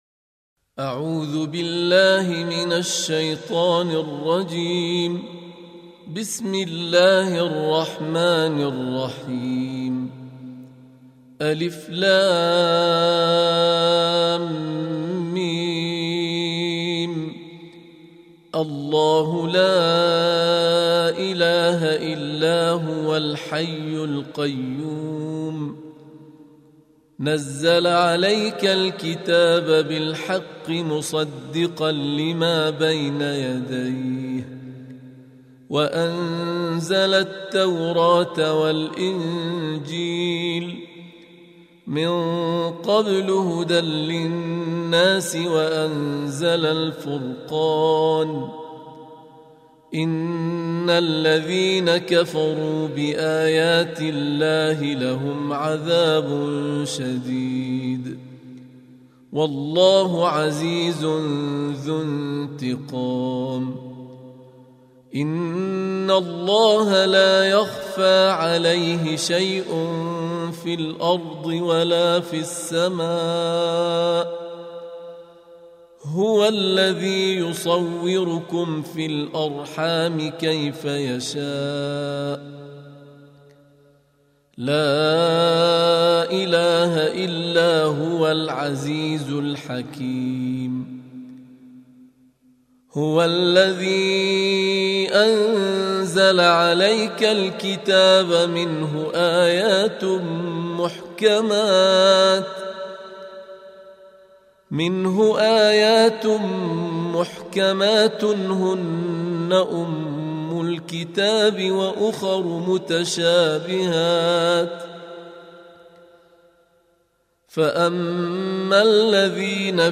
Surah Sequence تتابع السورة Download Surah حمّل السورة Reciting Murattalah Audio for 3. Surah �l-'Imr�n سورة آل عمران N.B *Surah Includes Al-Basmalah Reciters Sequents تتابع التلاوات Reciters Repeats تكرار التلاوات